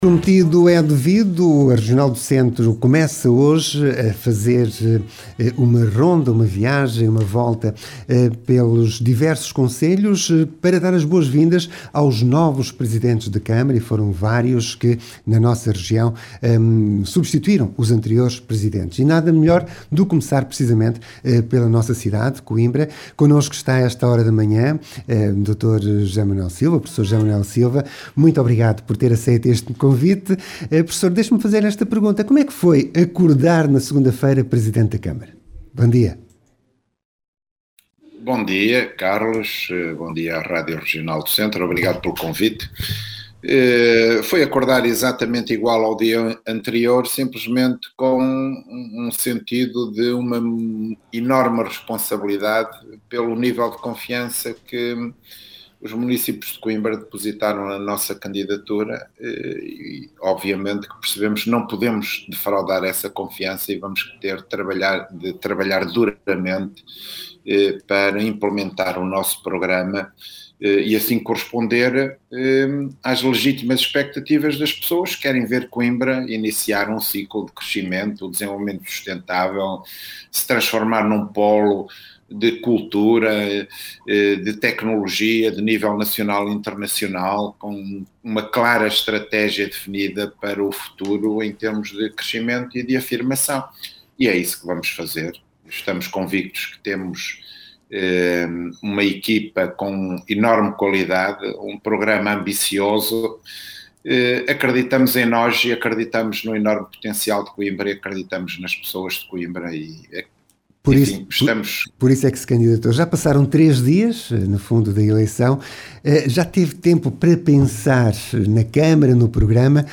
O novo presidente da Câmara Municipal de Coimbra, José Manuel Silva, esteve hoje, em directo, na Rádio Regional do Centro e falou dos principais objectivos para o mandato que se avizinha.
Entrevista de José Manuel Silva à Rádio Regional do Centro